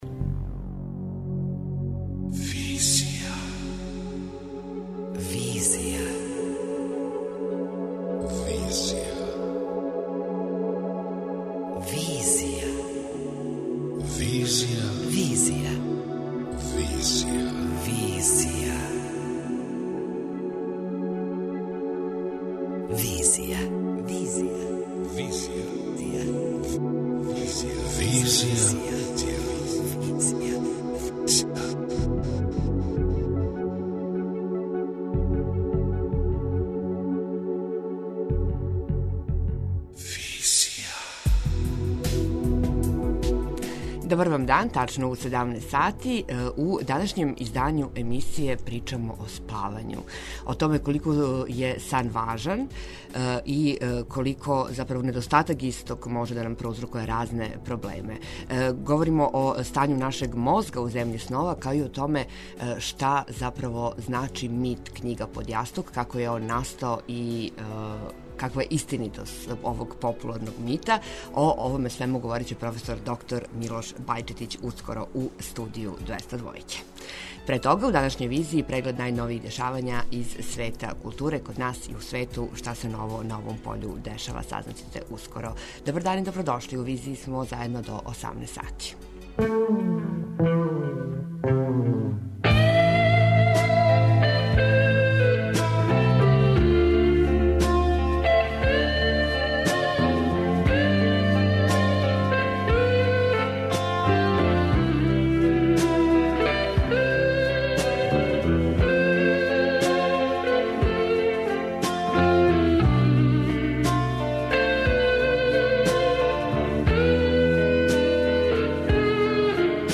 преузми : 26.07 MB Визија Autor: Београд 202 Социо-културолошки магазин, који прати савремене друштвене феномене.